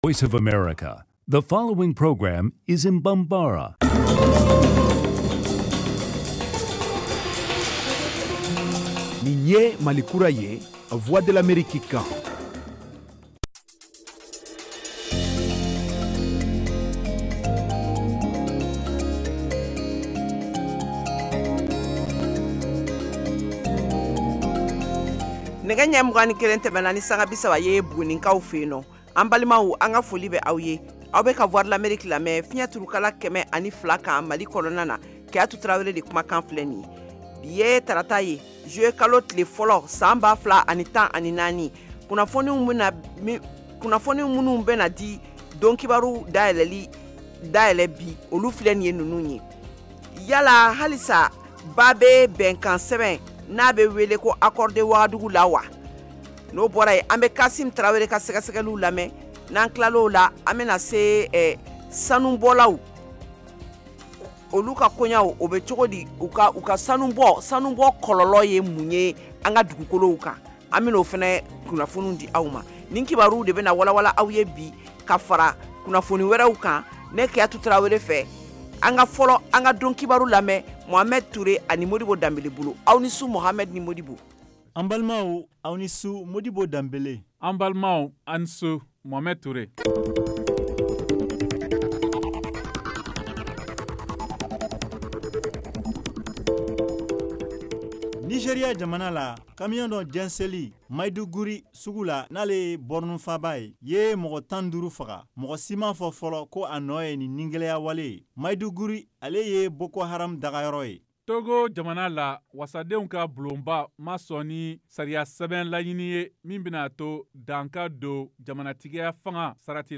Emission quotidienne en langue bambara
en direct de Washington. Au menu : les nouvelles du Mali, les analyses, le sport et de l’humour.